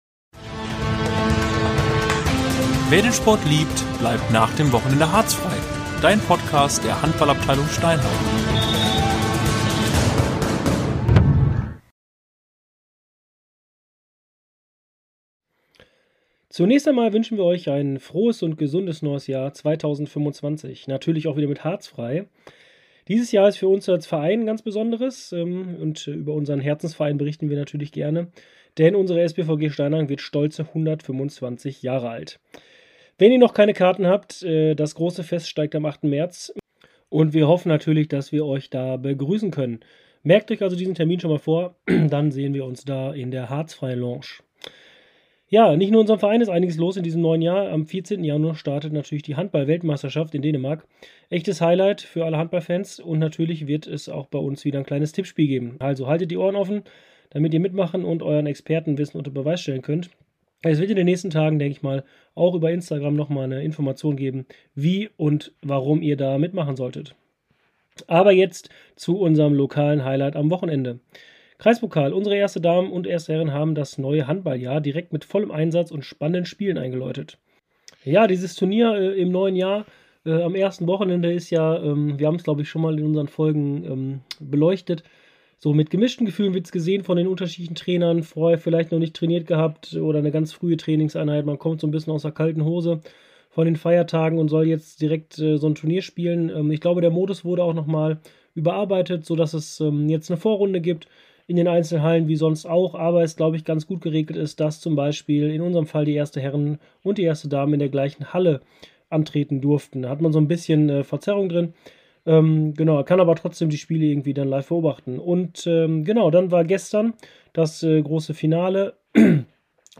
In der ersten Folge 2025 blicken wir auf den Kreis-Pokal zurück, hören Stimmen von Trainern und feiern ein besonderes Jahr: Die SpVG Steinhagen wird 125 Jahre alt! Außerdem ein Ausblick auf die Handball-WM in Dänemark und unser kommendes Tippspiel.